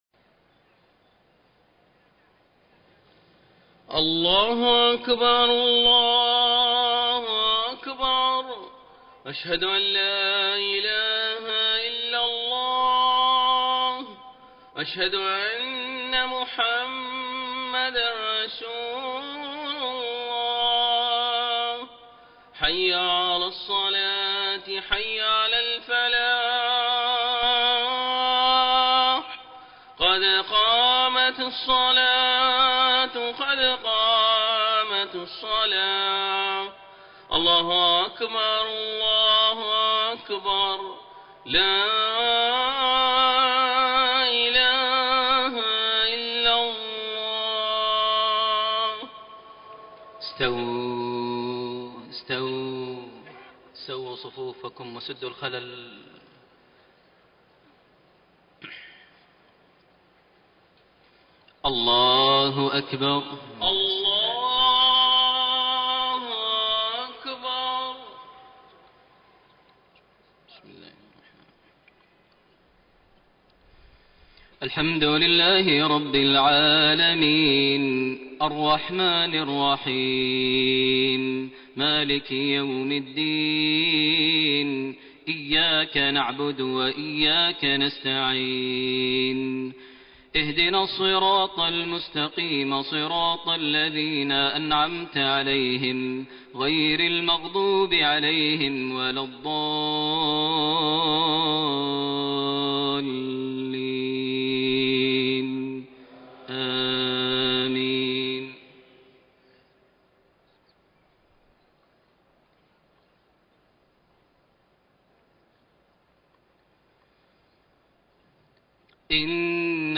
صلاة المغرب 26 جمادى الاولى 1433هـ خواتيم سورة الزخرف 74-89 > 1433 هـ > الفروض - تلاوات ماهر المعيقلي